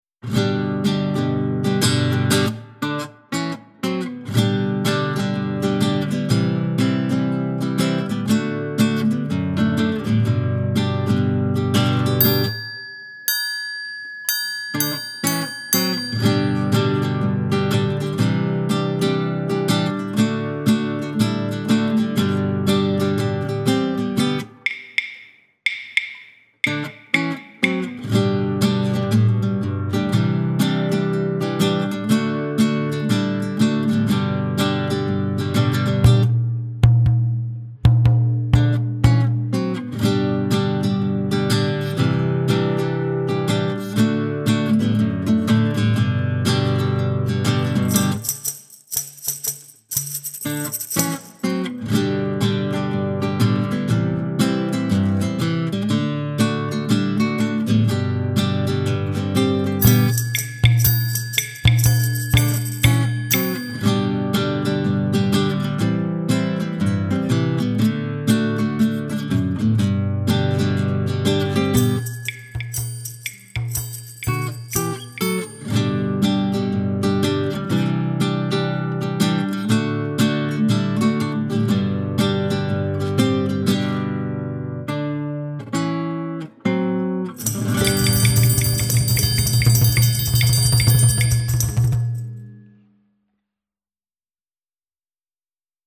Sobresolamente instrumentales